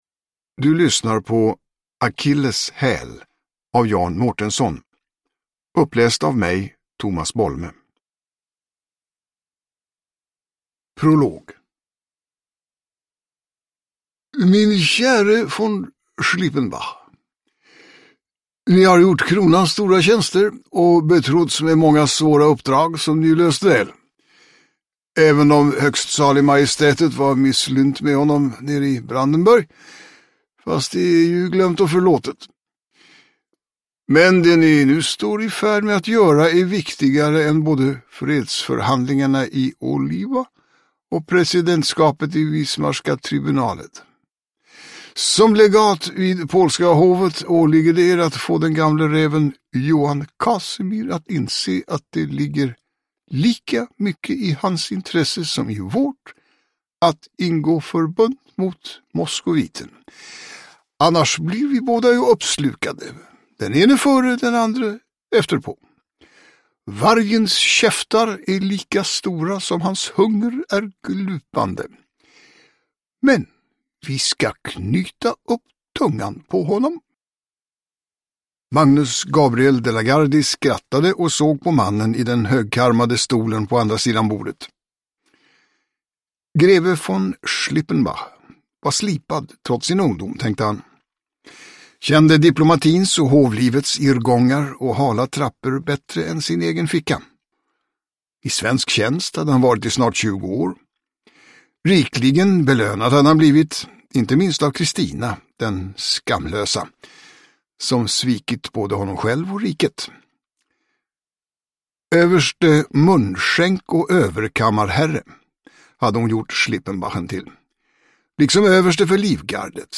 Uppläsare: Tomas Bolme
Ljudbok